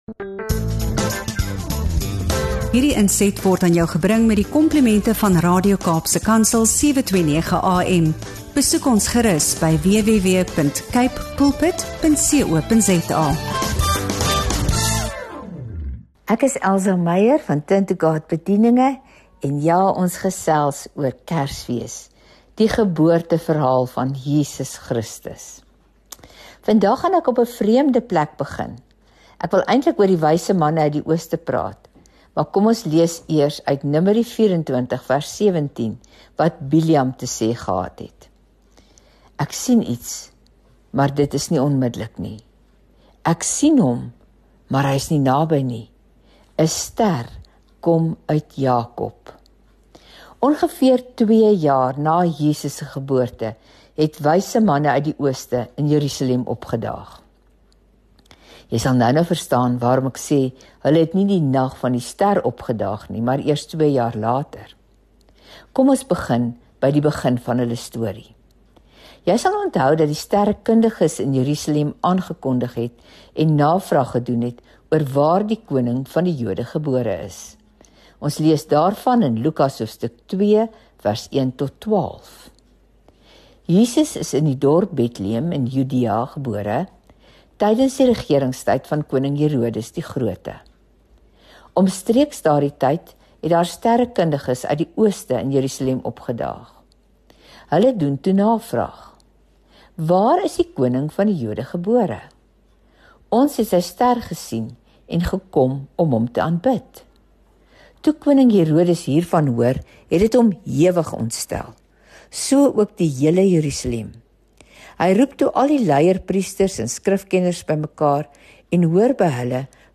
FOCUS & FOKUS DEVOTIONALS